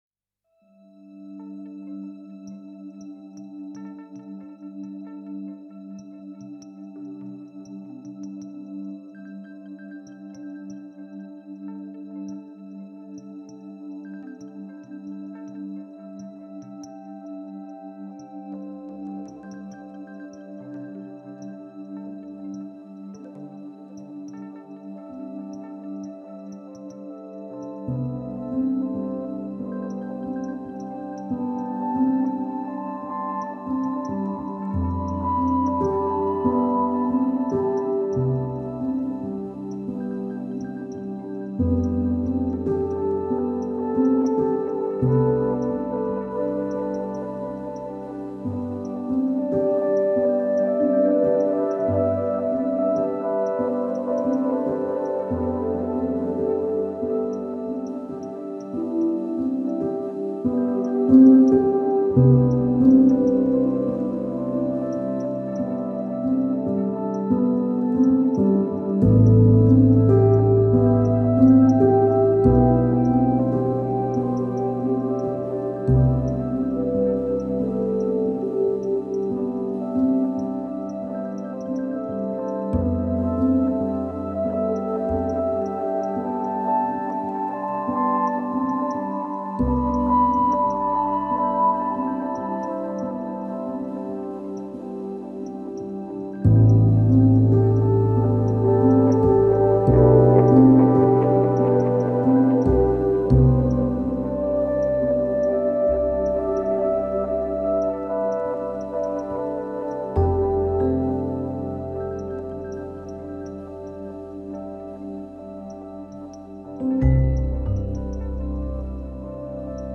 Textural layers, tones and natural atmosphere.